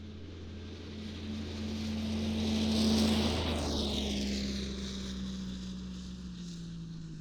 Compression Ignition Snowmobile Description Form (PDF)
Compression Ignition Subjective Noise Event Audio File (WAV)